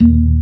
FST HMND D#1.wav